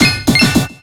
Cri d'Archéomire dans Pokémon X et Y.